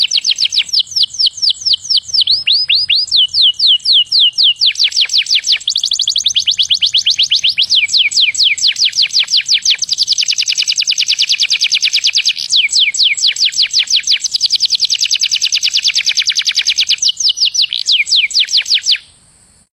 ringtone pajarito 5